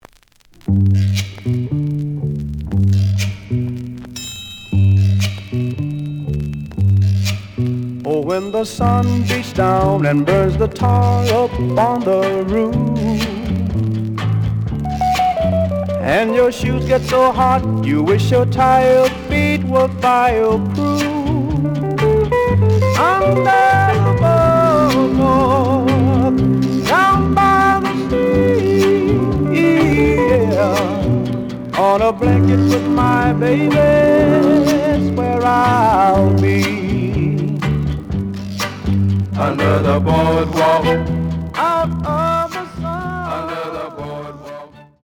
The audio sample is recorded from the actual item.
●Genre: Rhythm And Blues / Rock 'n' Roll
Slight noise on both sides.)